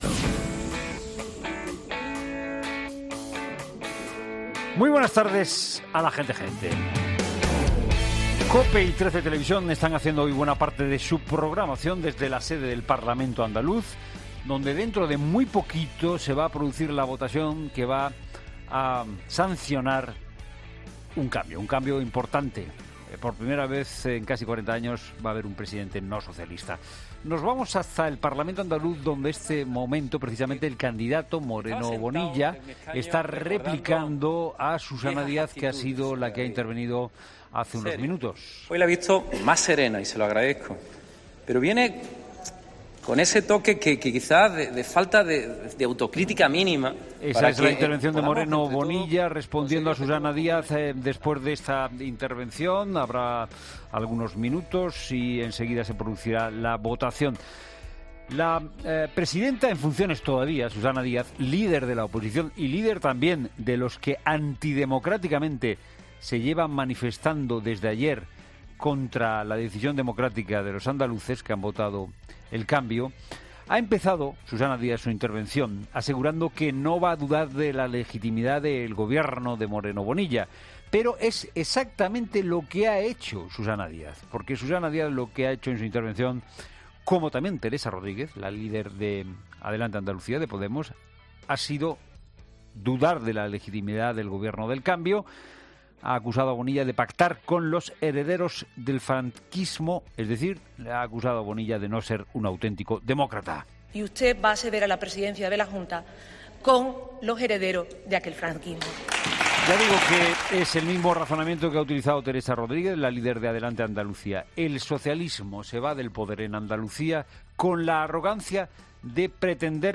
Monólogo de Fernando de Haro
COPE y TRECE están haciendo hoy buena parte de su programación desde la sede del Parlamento Andaluz, donde dentro de muy poquito se va a producir la votación que va a sancionar un cambio.